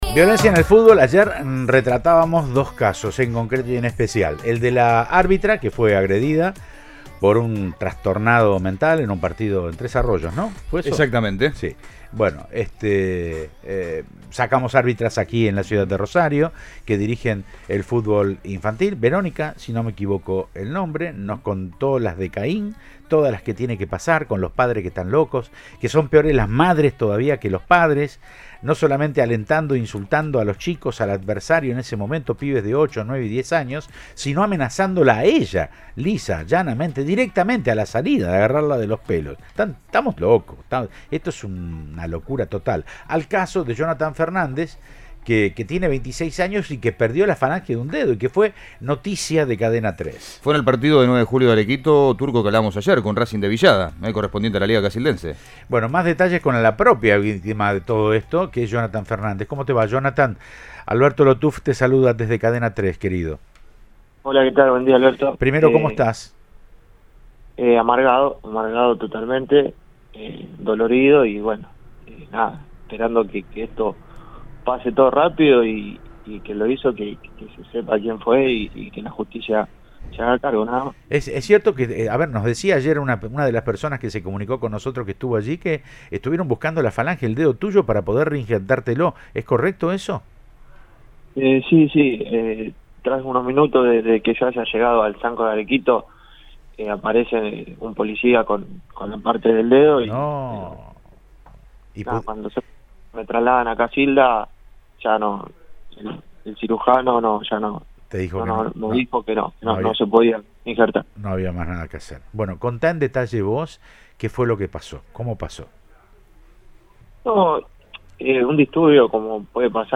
Audio. El desgarrador relato del jugador al que le cortaron el dedo